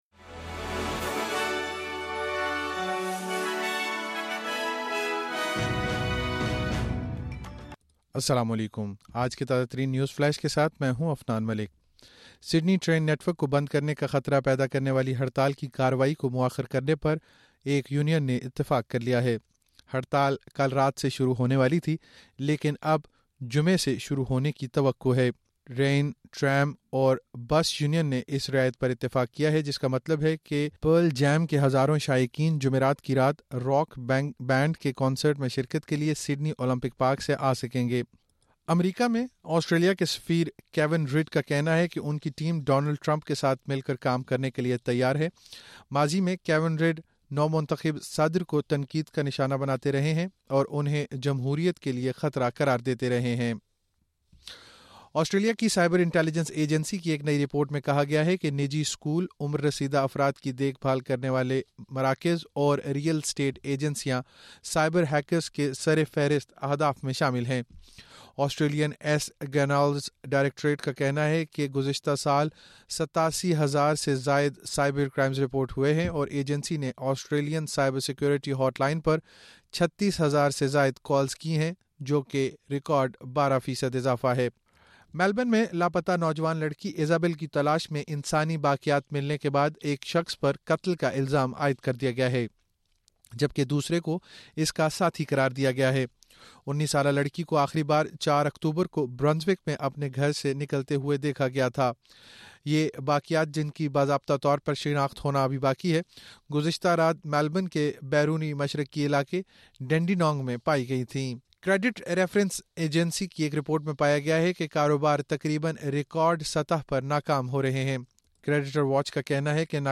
نیوز فلیش: 20 نومبر 2024 کی مختصر خبریں